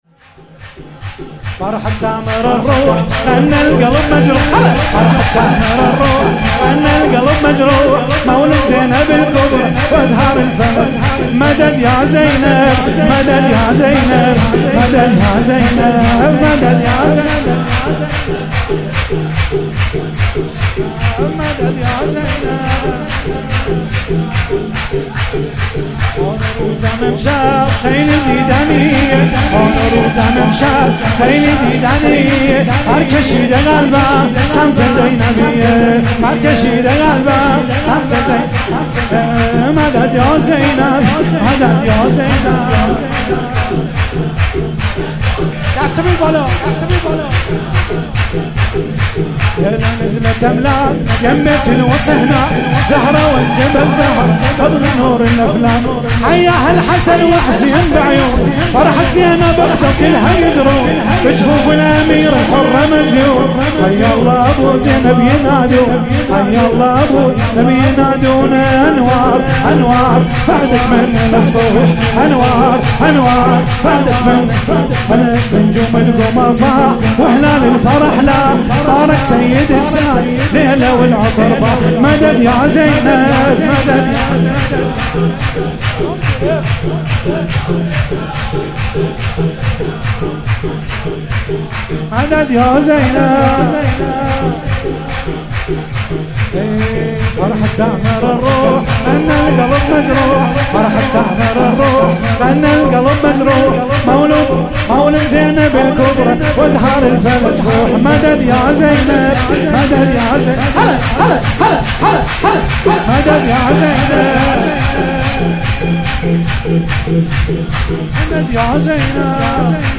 ولادت حضرت زینب کبری(س)